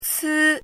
汉字“刺”字是一个多音字,“刺”的拼音是：cì、cī。
“刺”读音
国际音标：tsʰɿ˥˧;/tsʰɿ˥
cī.mp3